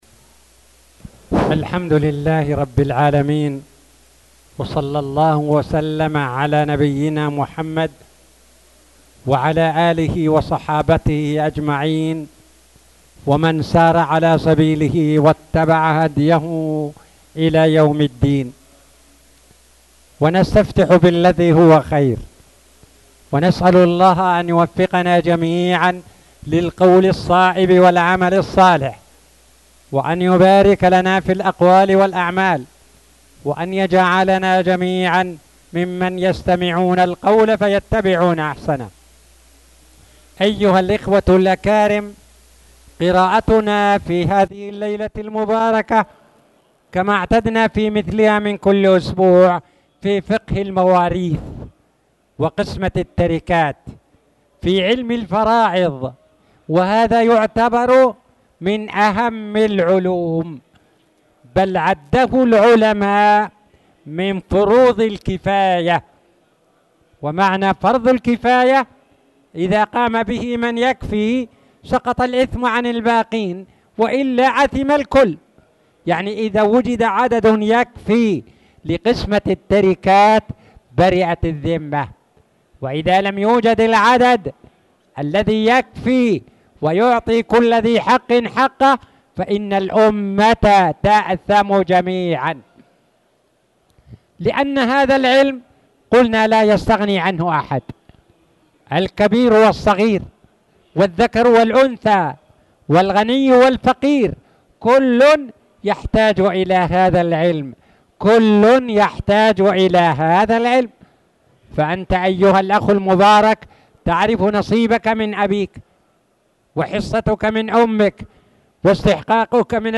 تاريخ النشر ١٣ ربيع الأول ١٤٣٨ هـ المكان: المسجد الحرام الشيخ